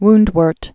(wndwûrt, -wôrt)